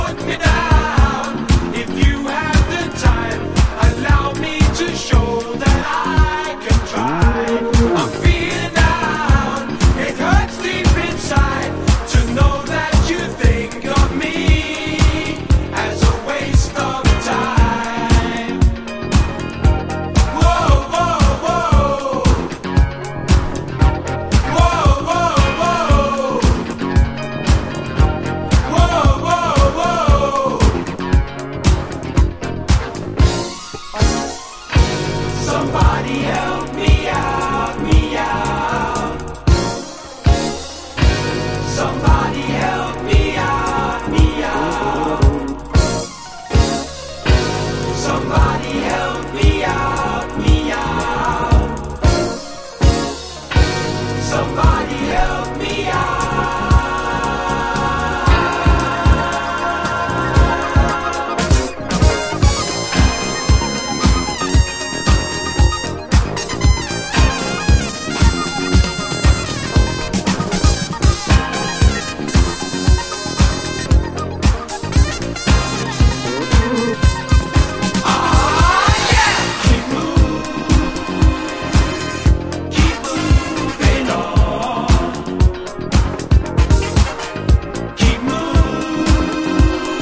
当時大ヒットを記録したジャズファンク・ディスコ大名曲！